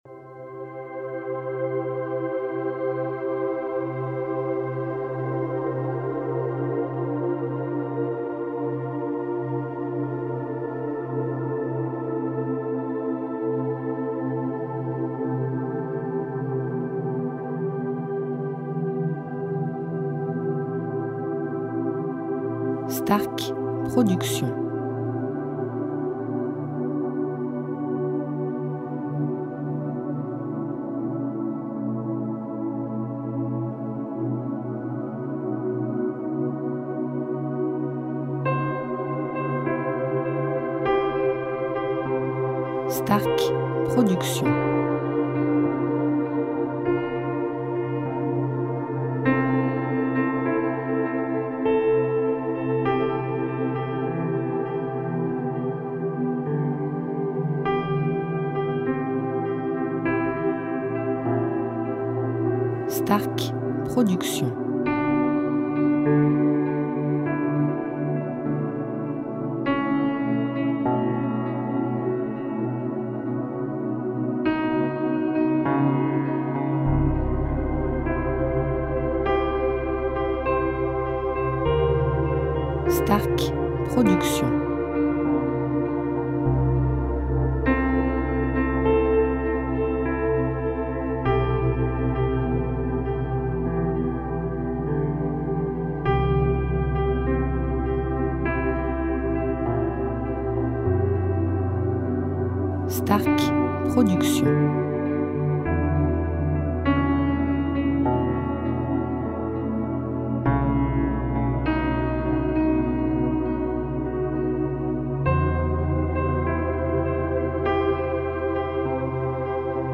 style Sophrologie Méditation Relaxant durée 1 heure